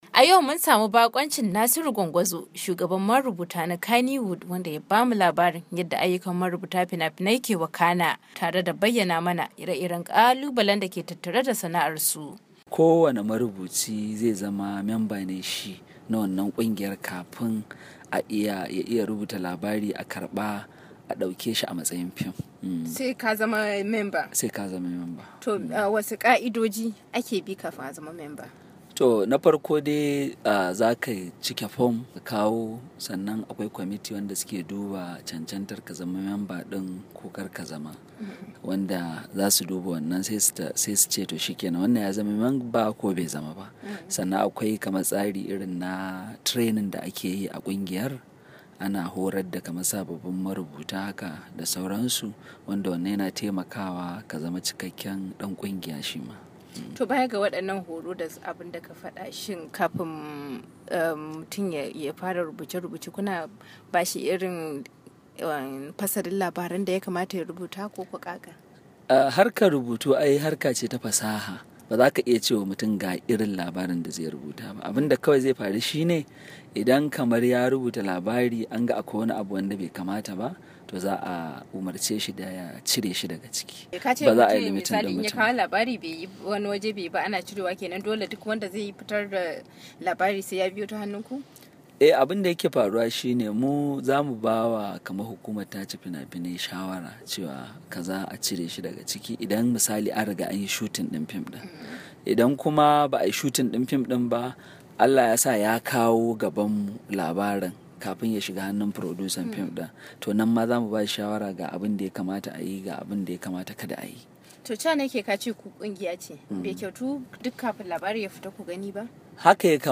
Ya bayyana hakan ne a yayin da suke tattaunawa da wakiliyar dandalinVOA a wata zantawa da suka da ita.